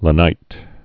(lə-nīt)